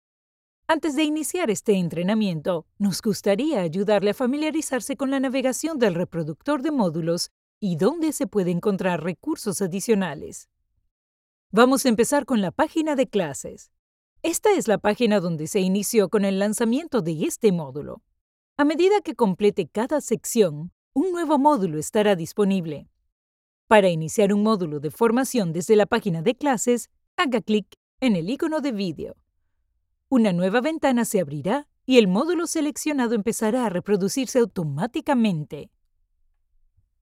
Her professional, motherly tone delivers warmth and credibility for commercials, e-learning, and narration seeking authentic Latin American voiceover.
Corporate Videos
ContraltoLowMezzo-SopranoSoprano